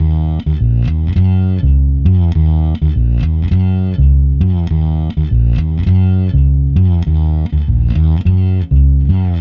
Ala Brzl 1 Fnky Bass-D#.wav